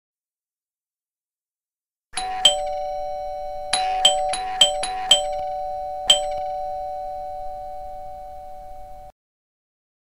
Door bell sound effect (3)